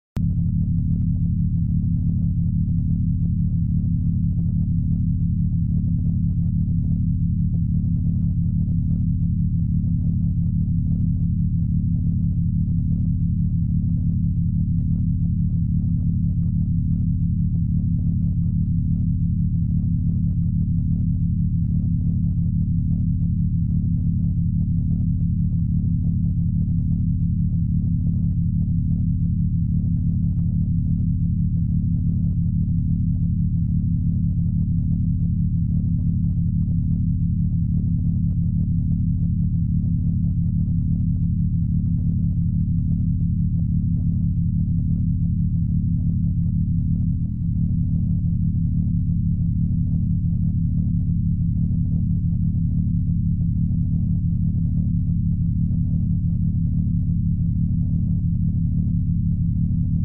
Elevate your focus game with 40 hz binaural beats